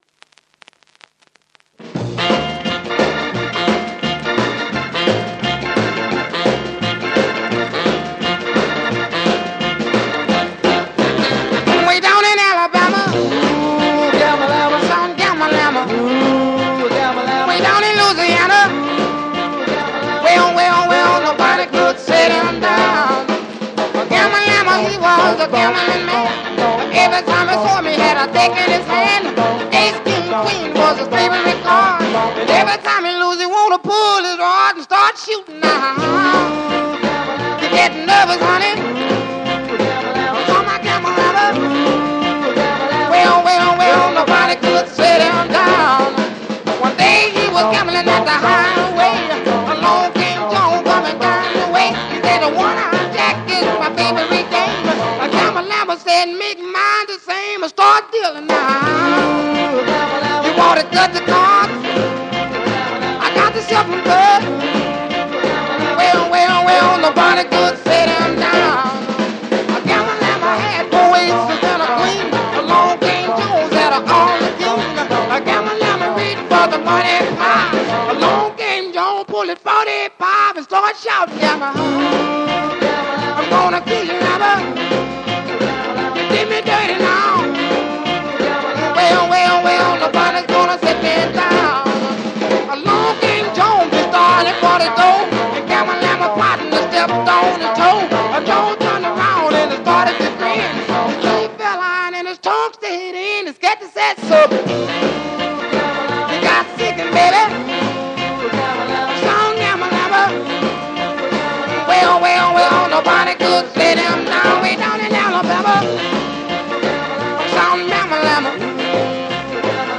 Soul 2Siders
Vinyl show some light hairlines but plays really well.